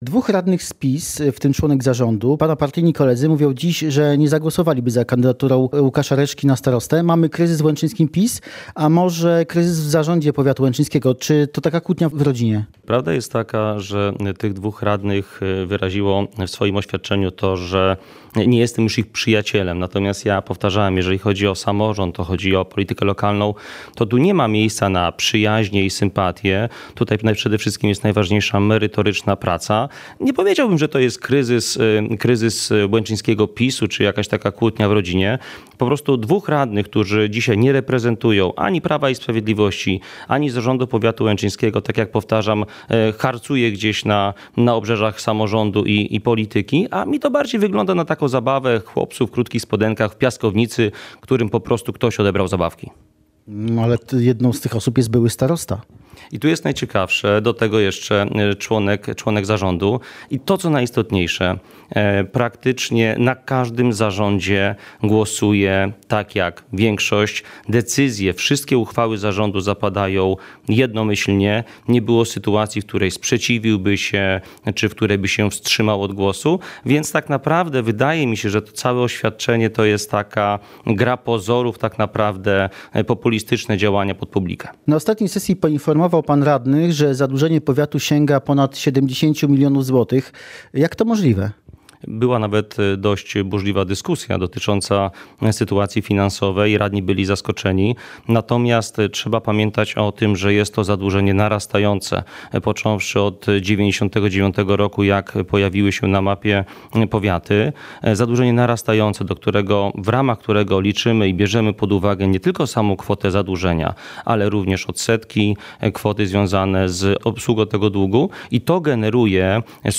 Ze starostą łęczyńskim Łukaszem Reszką o rekordowym zadłużeniu powiatu, poprawie relacji z okolicznymi gminami, inwestycjach oraz konflikcie w zarządzie powiatu